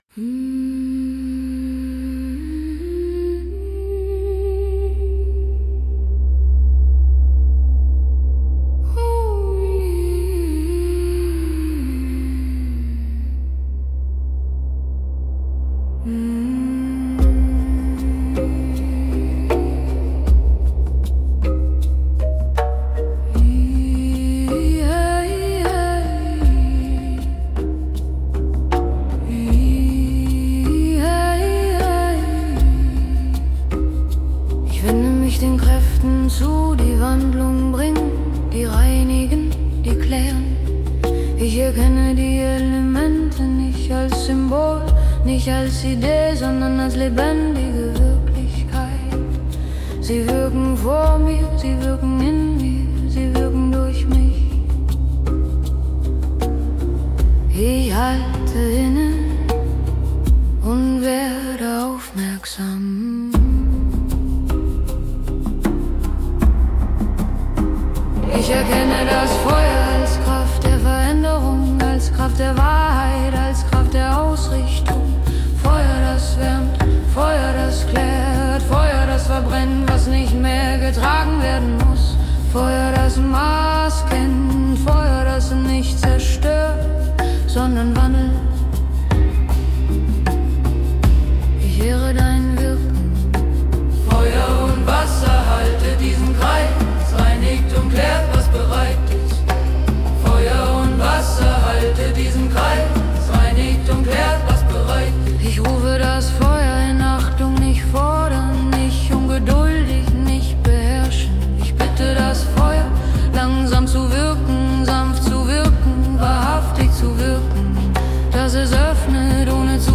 Die Lieder sind lang, ruhig, repetitiv und bewusst schlicht.
• klare Sprache, langsamer Puls